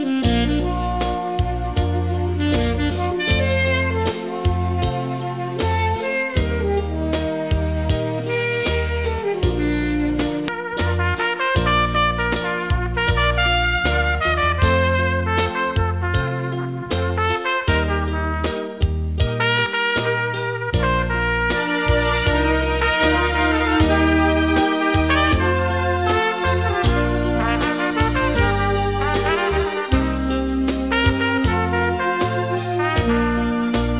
trumpet
Sax